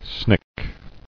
[snick]